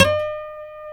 E 5 HAMRNYL.wav